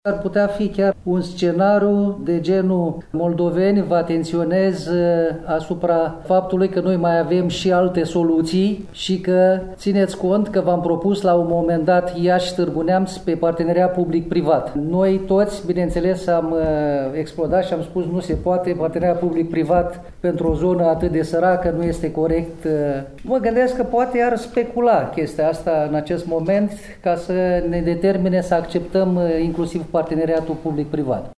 Într-o conferinţă de presă, susţinută la Iaşi de asociaţiile „Împreună pentru A8” şi „Moldova vrea autostradă”, reprezentanţii acestor organizaţii civice au demontat informaţiile, vehiculate de Ministerul Transporturilor şi necontrazise de Compania Naţională de Administrare a Infrastructurii Rutiere, privind faptul că A8, Ungheni – Iaşi – Tîrgu Mureş ar trebui înlocuită cu A13, care ar urma să lege Bacăul de Braşov.